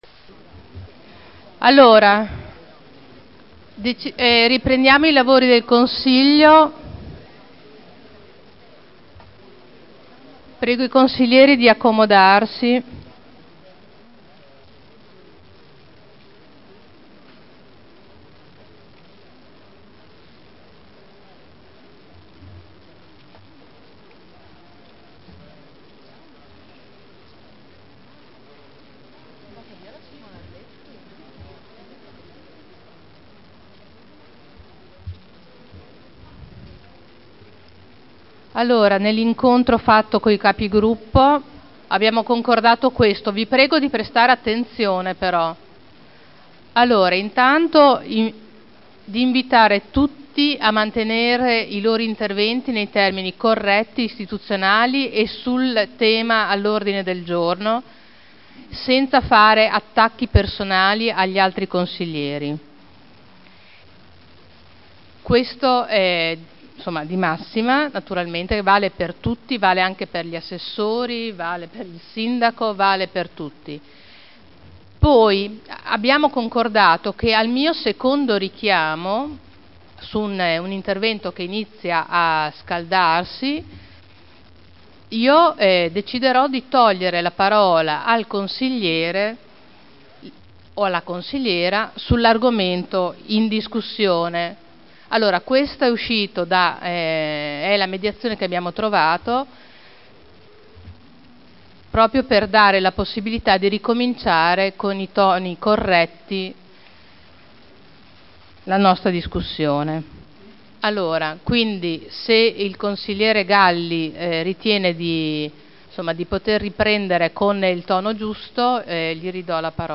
Presidente — Sito Audio Consiglio Comunale
Seduta del 25/01/2010. Sospende la seduta e convoca la conferenza dei Capi Gruppo.